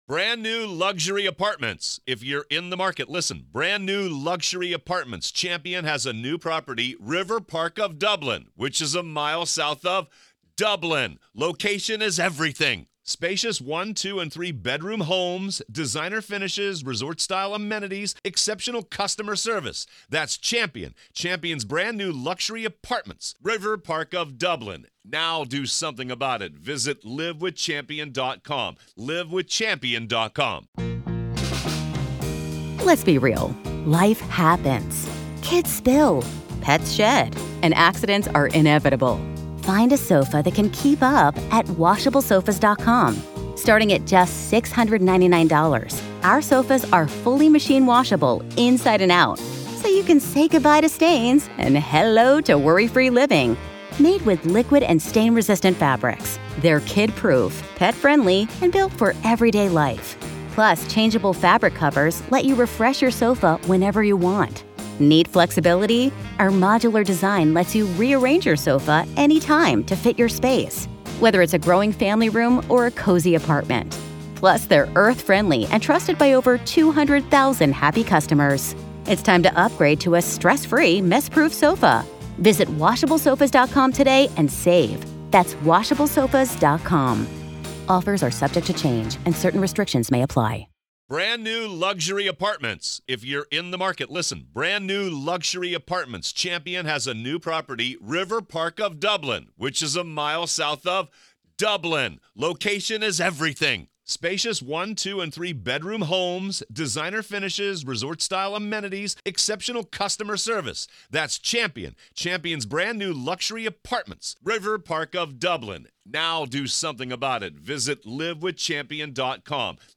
From high-profile criminal trials to in-depth examinations of ongoing investigations, this podcast takes listeners on a fascinating journey through the world of true crime and current events. Each episode navigates through multiple stories, illuminating their details with factual reporting, expert commentary, and engaging conversation.